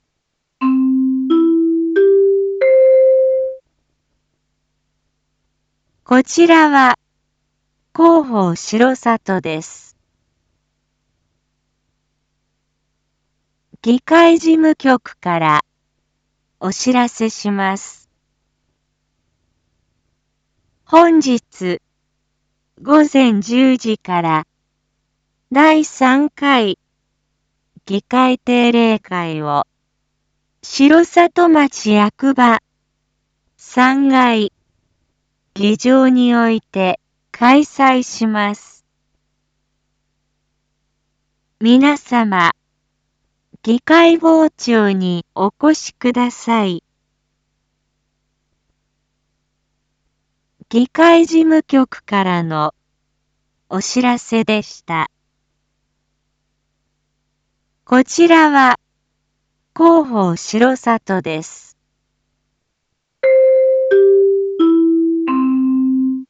一般放送情報
Back Home 一般放送情報 音声放送 再生 一般放送情報 登録日時：2023-09-05 07:01:08 タイトル：9/5 7時 第3回議会定例会 インフォメーション：こちらは広報しろさとです。